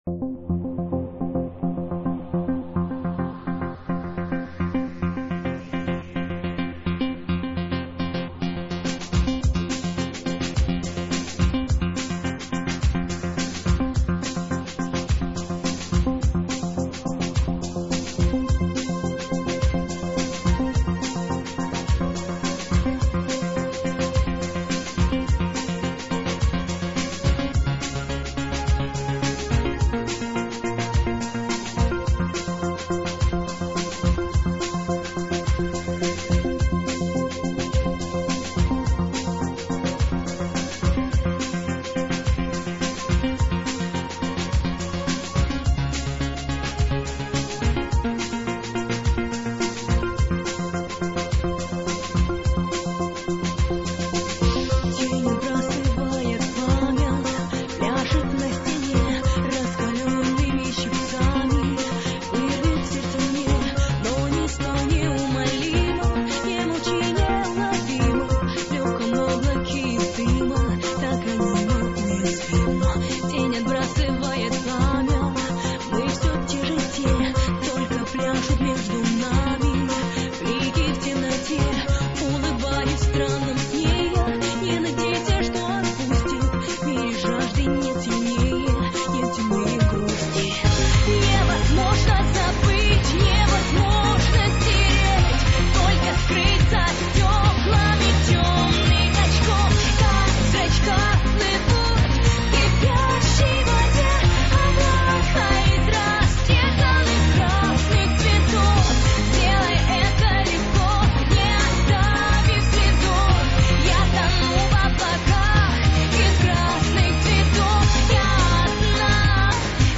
synth gothic